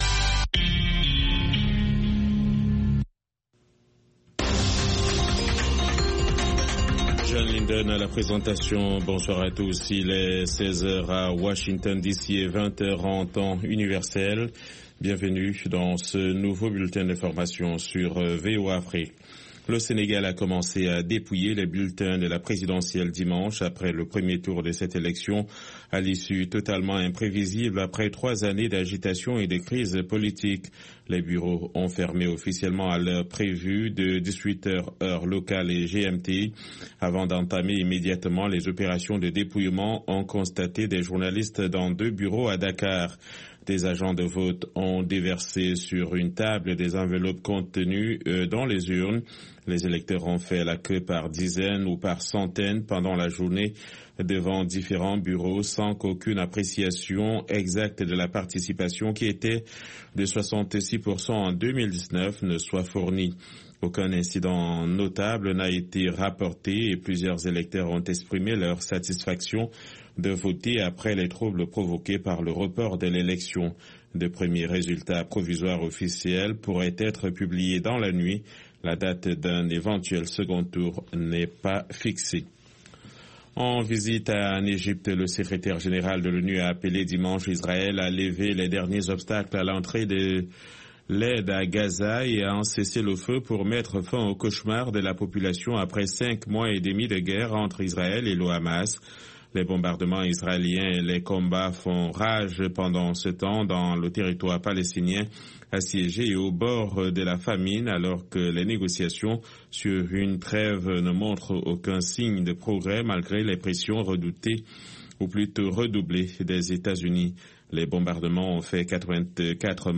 Blues and Jazz Program Contactez nous sur facebook